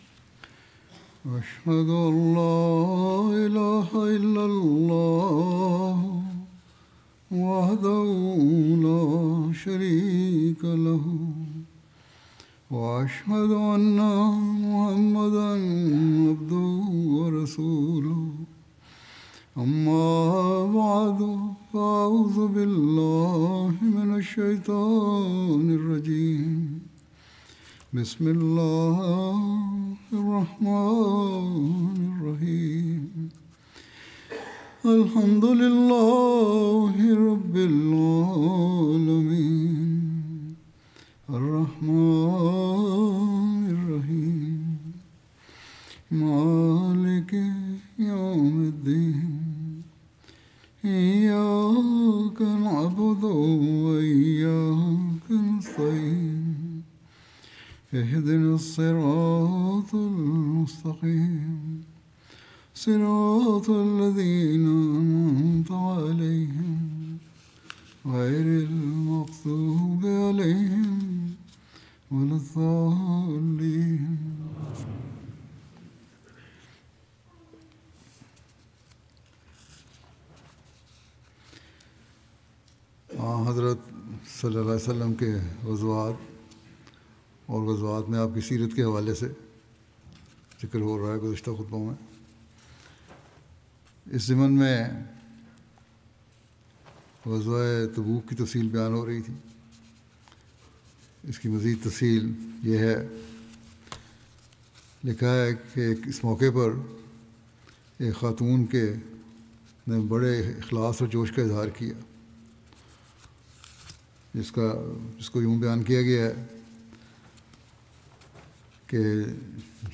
Urdu Friday Sermon by Head of Ahmadiyya Muslim Community
Urdu Friday Sermon delivered by Khalifatul Masih